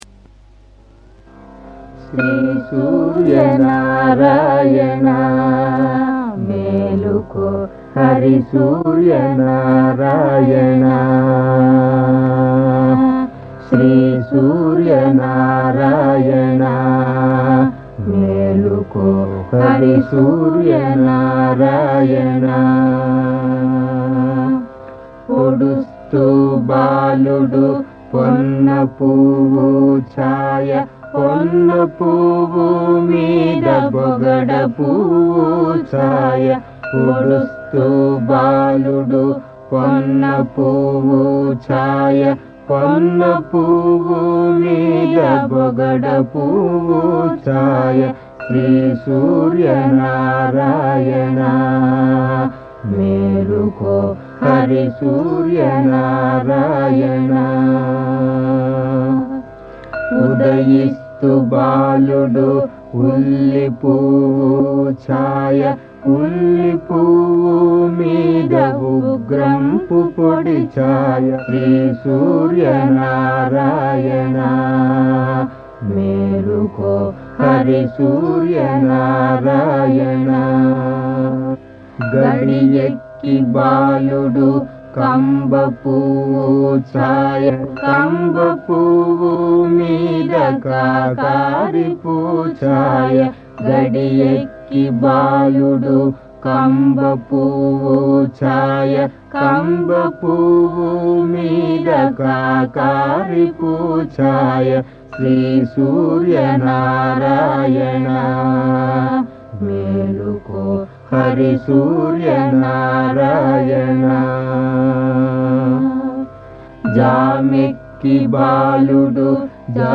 సూర్య స్తుతి